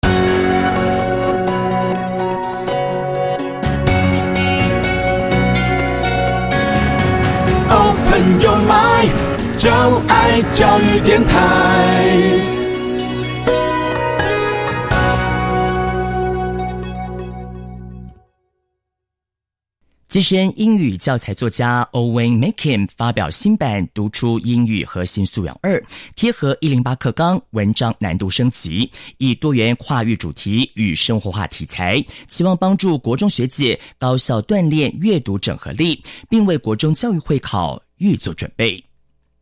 每篇文章搭配鮮豔彩圖圖解，幫助讀者融入主題情境，輔助單字圖像記憶與吸收，同時附母語人士朗誦的專業發音MP3，讀者可反覆聆聽，同時加強聽力能力。